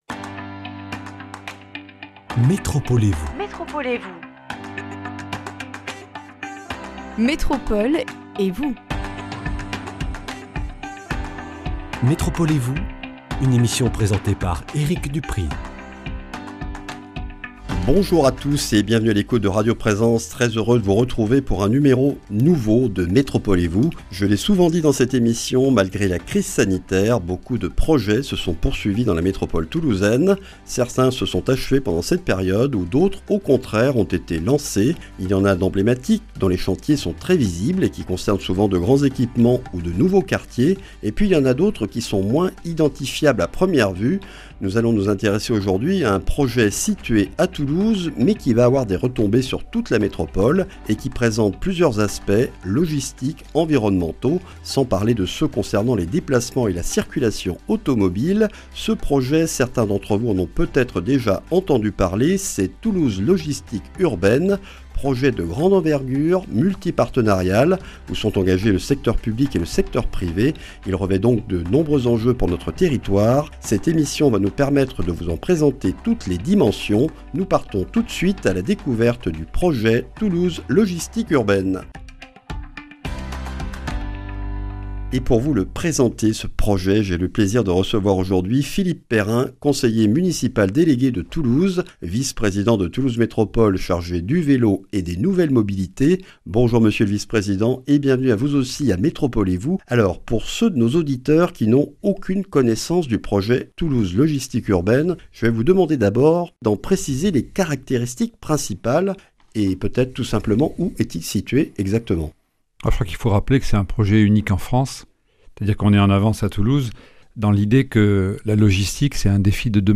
Philippe Perrin, conseiller municipal délégué de Toulouse, vice-président de Toulouse Métropole chargé du Vélo et des Nouvelles mobilités, est l’invité de ce numéro consacré au projet Toulouse Logistique Urbaine. Cette vaste zone logistique située à Fondeyre, à côté du MIN, fruit d’un partenariat public/privé, desservira le centre de Toulouse avec de petits utilitaires et des vélos-cargos. Un projet à la fois logistique et environnemental qui permettra de réduire le trafic poids lourds sur la rocade.